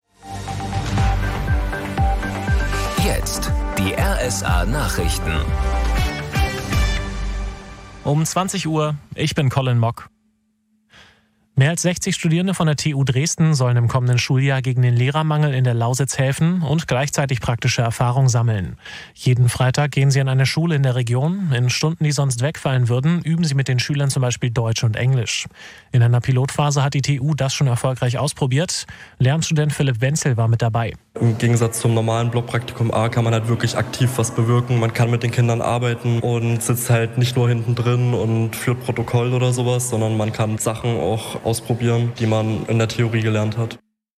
Nachrichten im Radio Sachsen